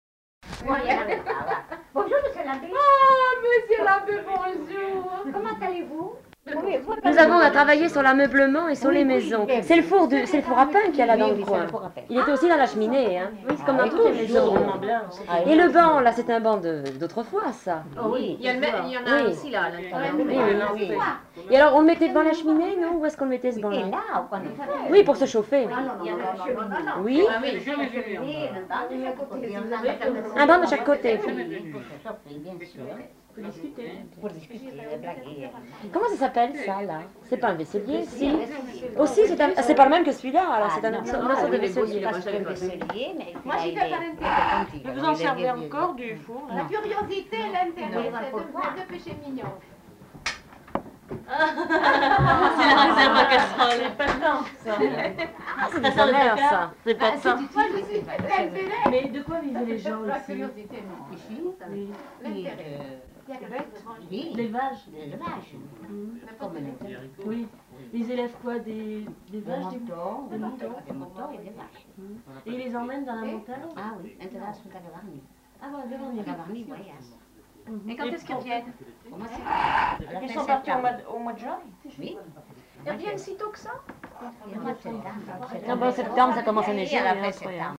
Aire culturelle : Bigorre
Genre : témoignage thématique
Notes consultables : Les deux informatrices ne sont pas identifiées.